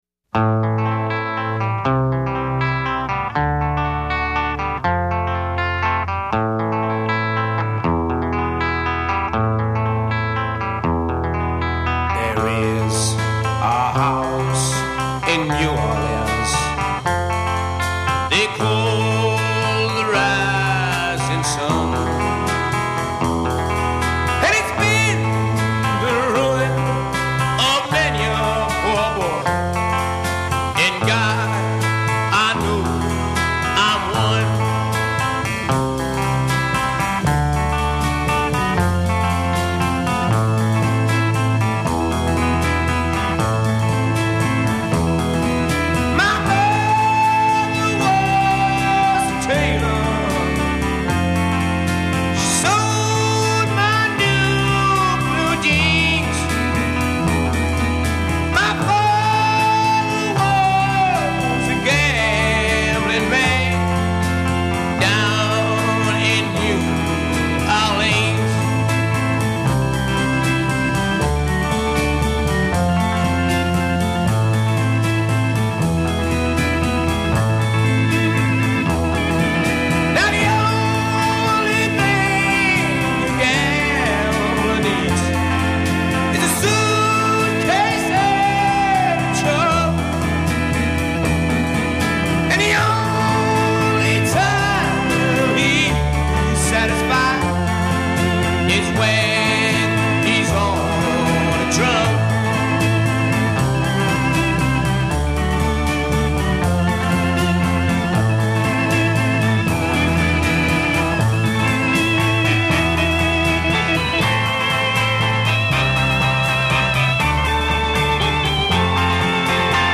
Intro 0:00 8 guitar solo
A verse 0: 14 vocal solo w/ ensemble a
A' verse : 14 organ solo
fill/outro   17 organ w/ ensemble
British Blues